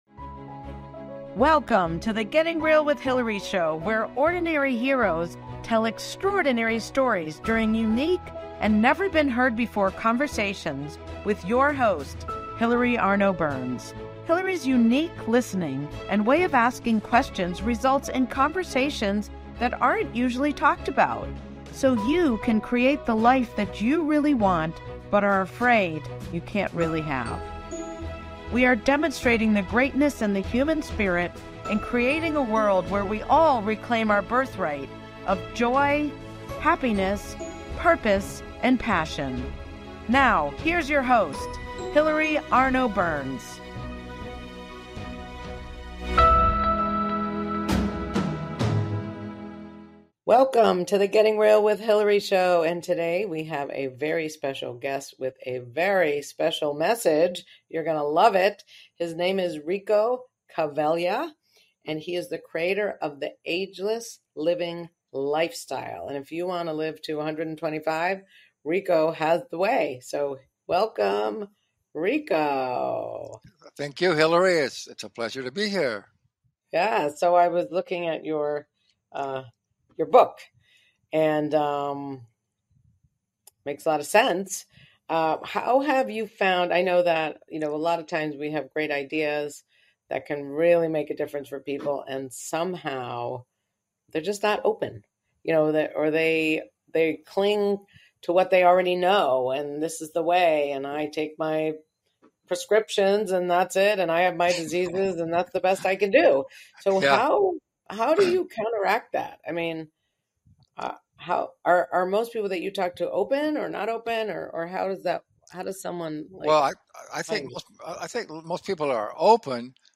Talk Show Episode
Are you tired of feeling tired?Are you sick of feeling sick?If you would like to hear how to live a healthier, more fun life, tune into this interview.